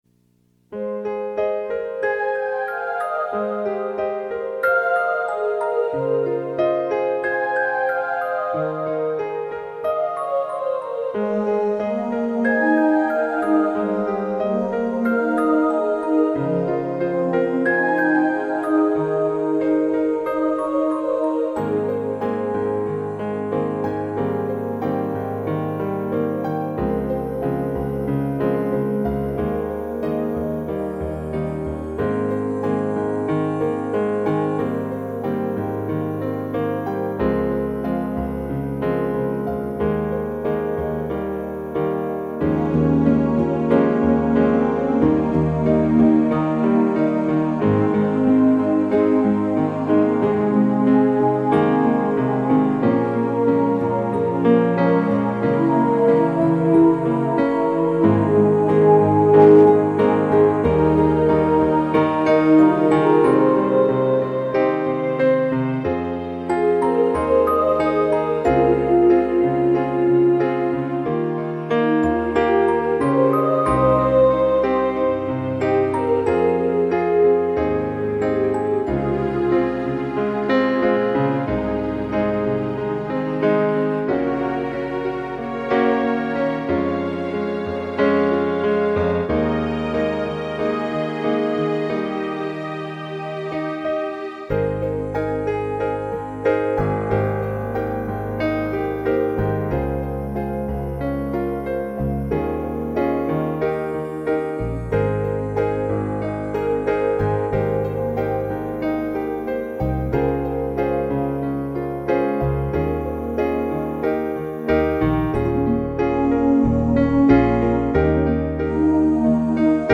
That was why I was able to compose my new uplifting song, which helps me heal.